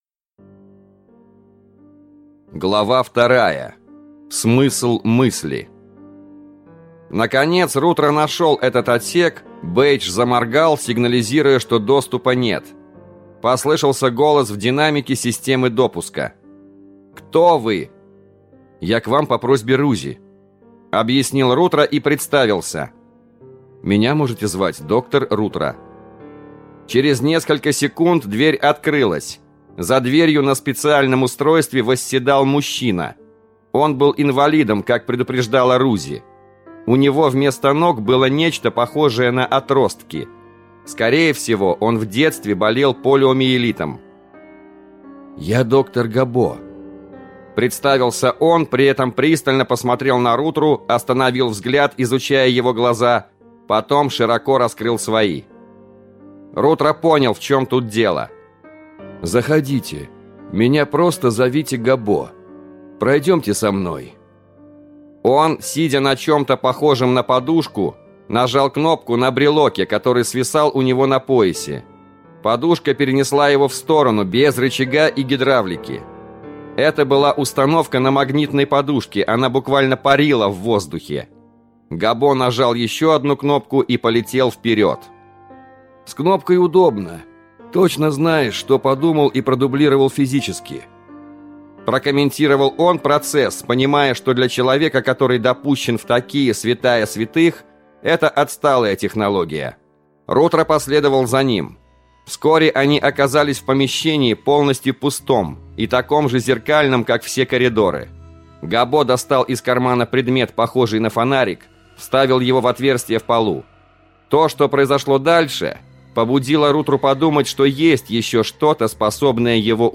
Аудиокнига Бинарный код. Mystery number two | Библиотека аудиокниг